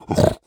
minecraft / sounds / mob / piglin / idle2.ogg